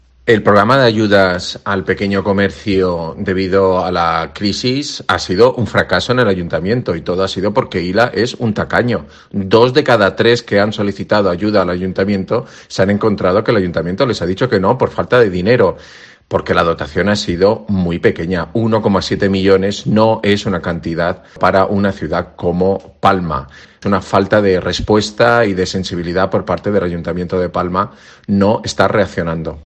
Julio Martínez, regidor del PP de Palma